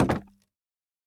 Minecraft Version Minecraft Version 1.21.5 Latest Release | Latest Snapshot 1.21.5 / assets / minecraft / sounds / block / bamboo_wood_fence / toggle1.ogg Compare With Compare With Latest Release | Latest Snapshot